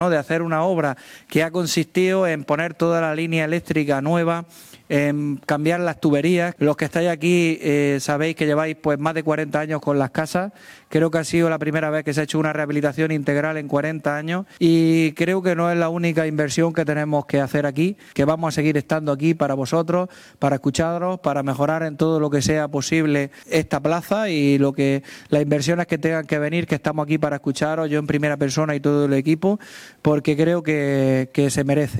30-03__inauguracion_plaza_isla_baleares_domingo.mp3